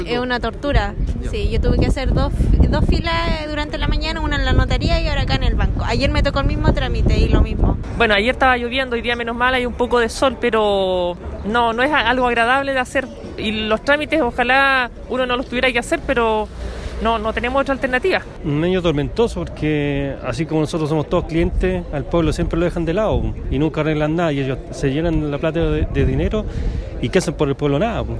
En ese sentido, esto opinan las decenas de clientes que esperan por horas la atención en la sucursal ubicada en calle Bulnes de Temuco.